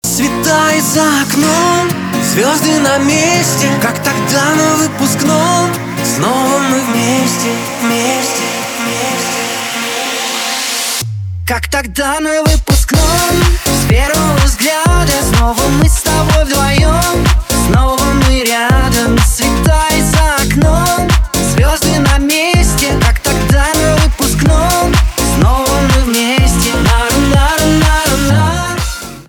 поп
гитара